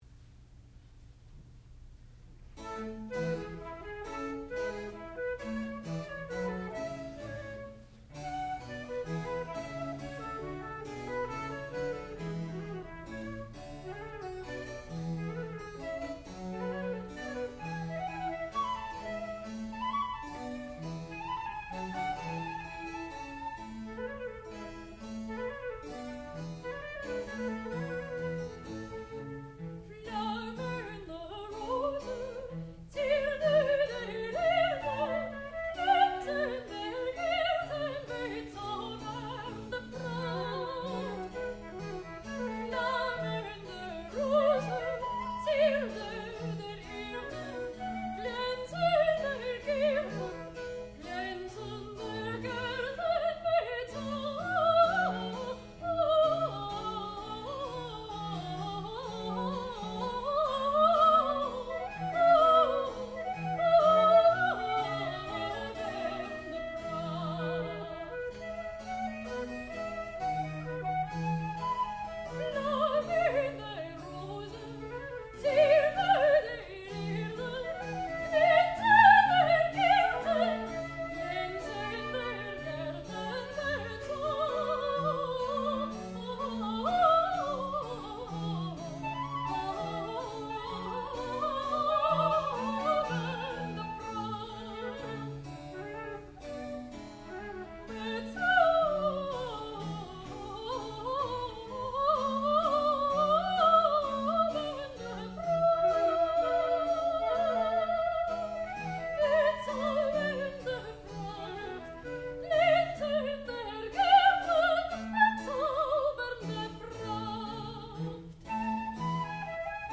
sound recording-musical
classical music
violoncello
harpsichord
soprano